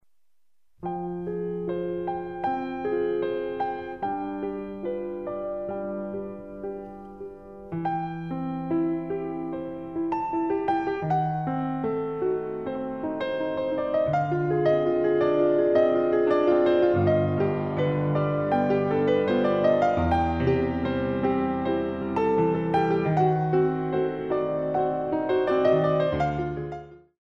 Compositions for Ballet Class
Some very Classical plus some jazz - ragtime rhythms
The CD is beautifully recorded on a Steinway piano.